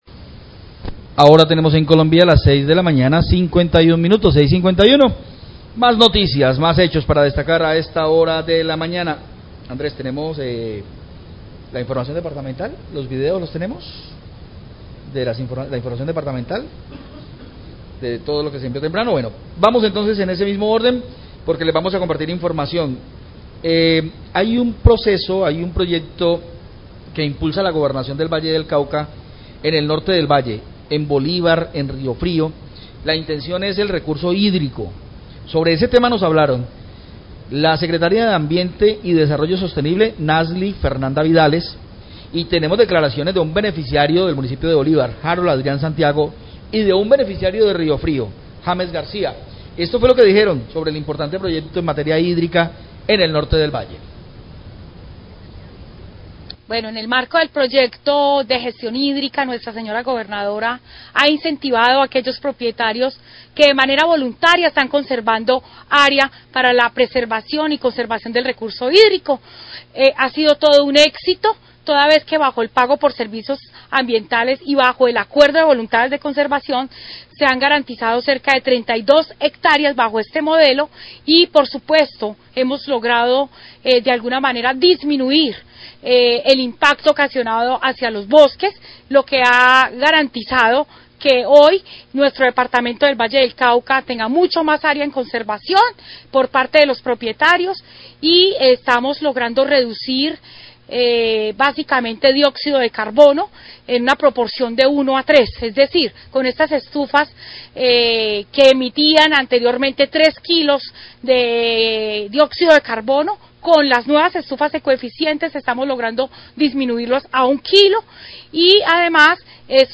Radio
La Secretaria de Ambiente y Desarrollo Sostenible del Valle, Nasly Vidales, habla del proyecto de conservación de fuentes hídricas en Bolívar y Ríofrio, que incluye también la entrega de estufas ecoeficientes.